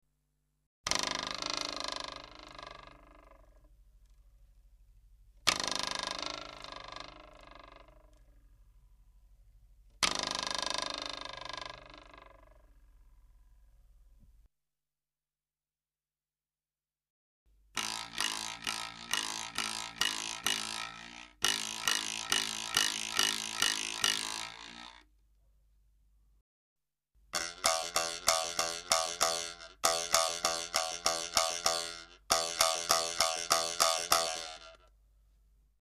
竹に切り込みが入っています　穴を閉じたり開けたりしながら　からだや手にあてて　音をだします